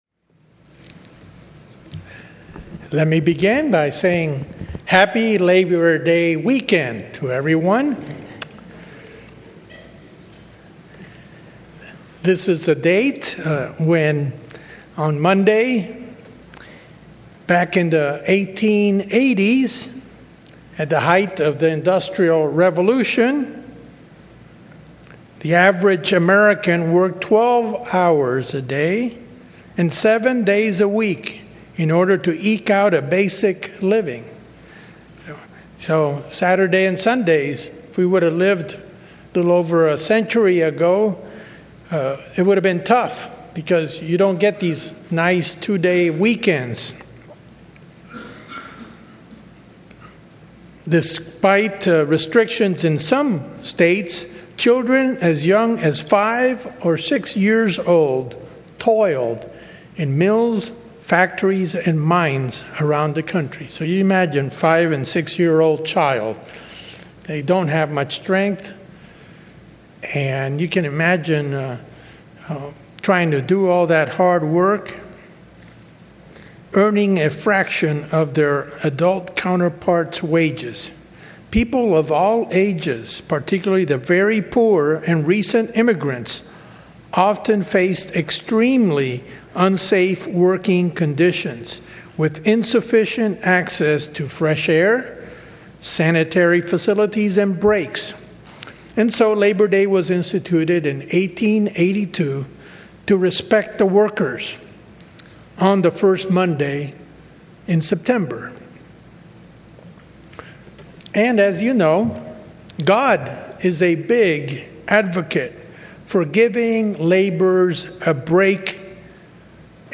On this Labor Day weekend, the pastor provides proof that the Bible and its laws respect the worker or citizen more than any man-made code of laws. He reviews scriptures regarding the Sabbath, Jubilee year, marriage and service.